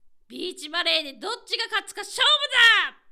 ボイス
パワフル